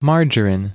Margarine (/ˈmɑːrərn/, also UK: /ˈmɑːrɡə-, ˌmɑːrɡəˈrn, ˌmɑːrə-/, US: /ˈmɑːrərɪn/
En-us-margarine.ogg.mp3